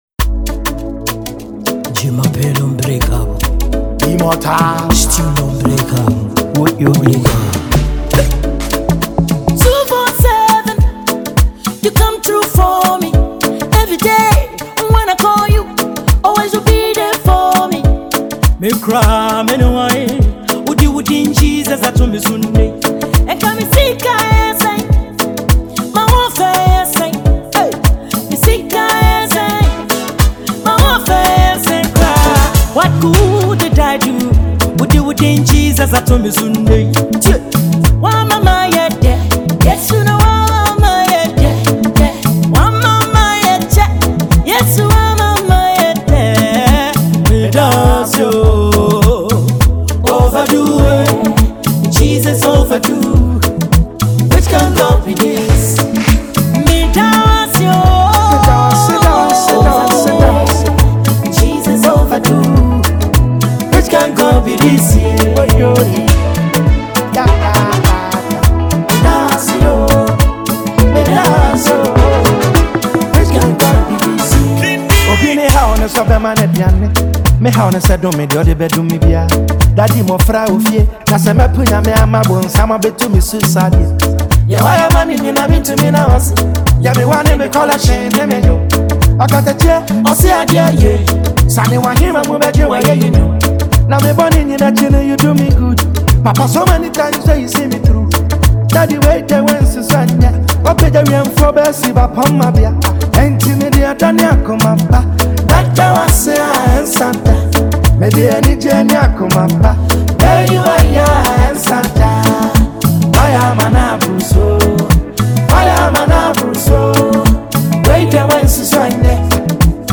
an award-winning Ghanaian gospel singer